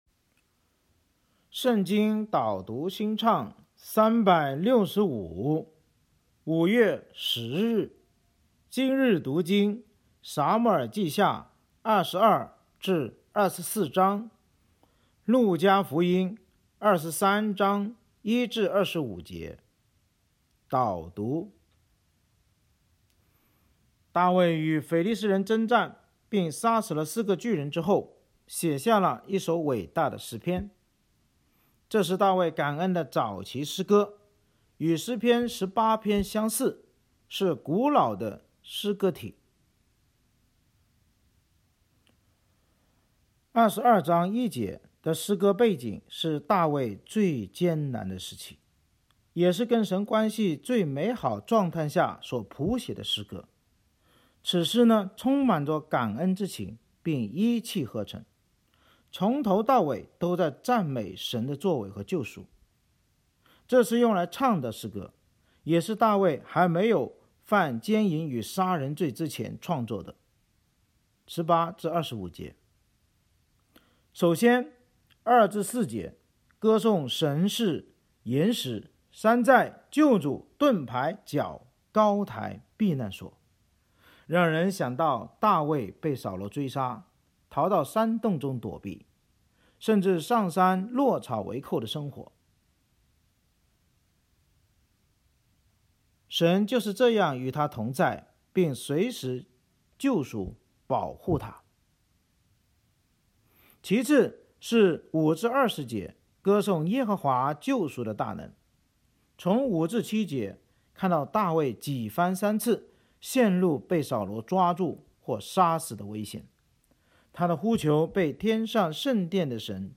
【经文朗读】（中文）